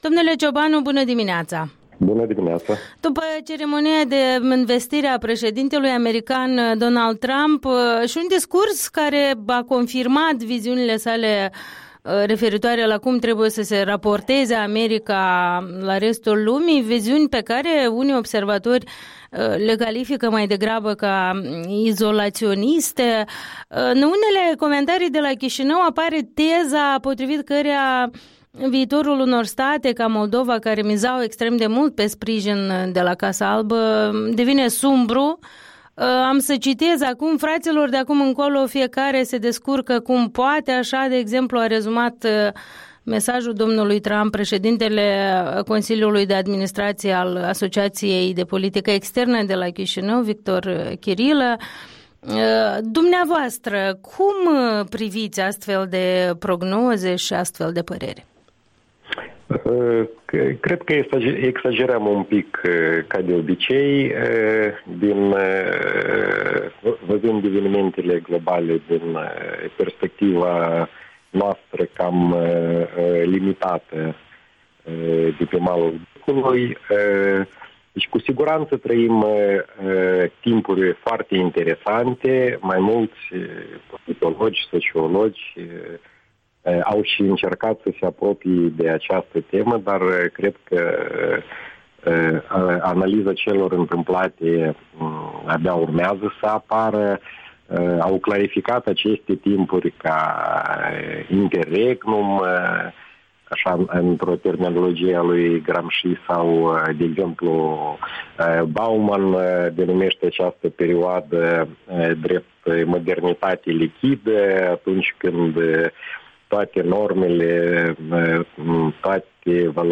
Interviul dimineții cu un analist politic despre zona est-europeană și politica externă previzibilă a noii administrații americane.